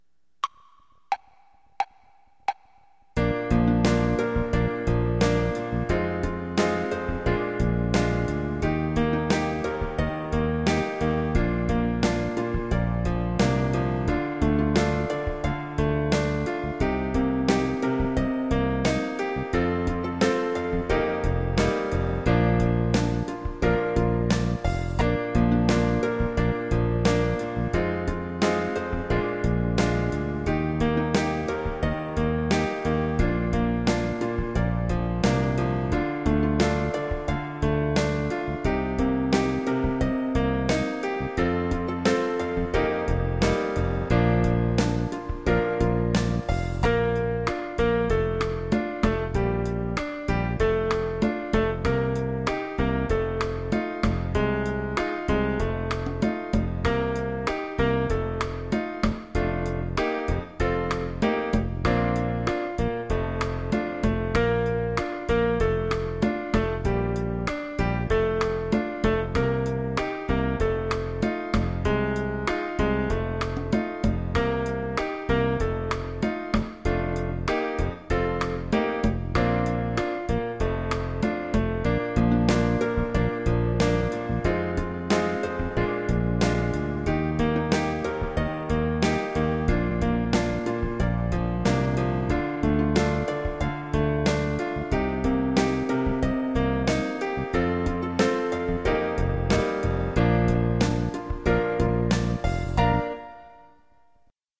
5 Samba 5 (harmony).mp3